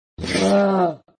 normal zombie die 2.mp3